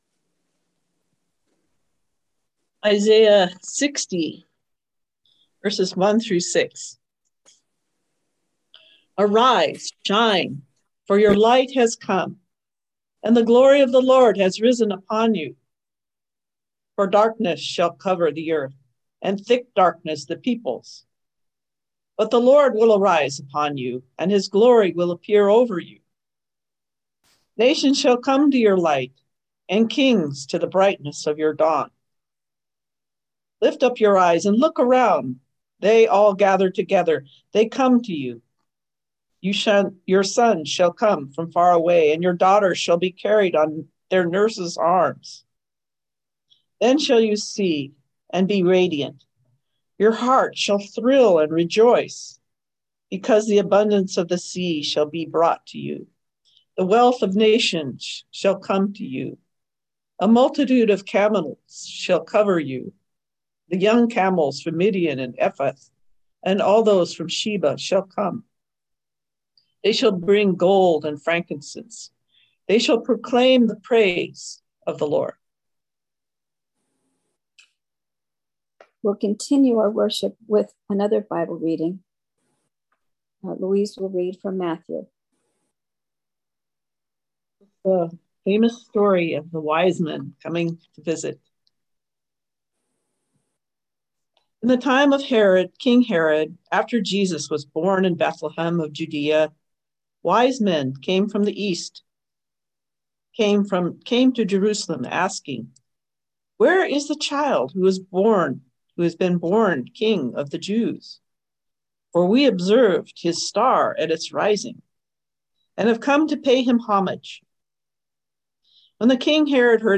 Listen to the most recent message from Sunday worship at Berkeley Friends Church, “The Wise Men.”